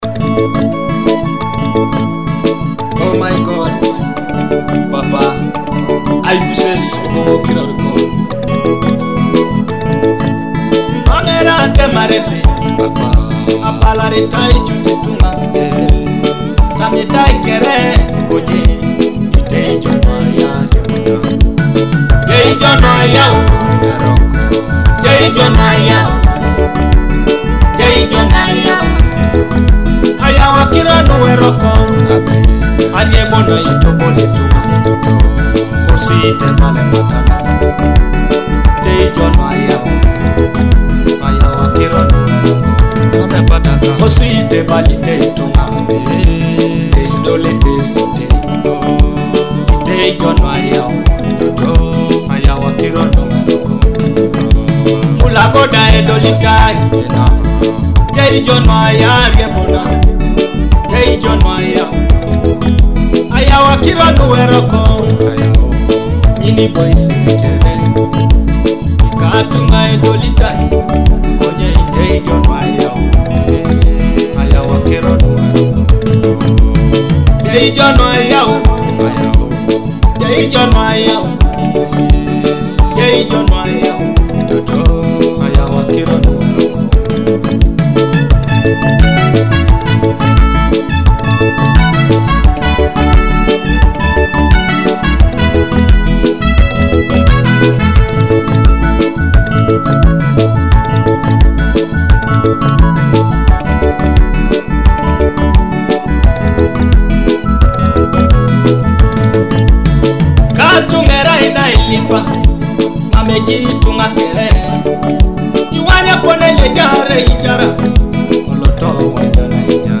touching gospel song
Through emotional vocals and meaningful lyrics